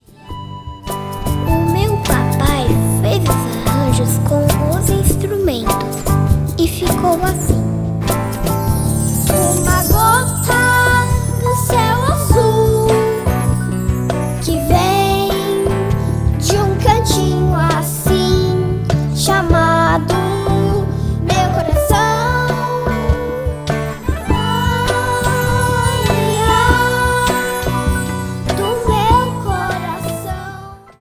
Violão, piano, baixo, escaleta, cajon, ganzá e bongô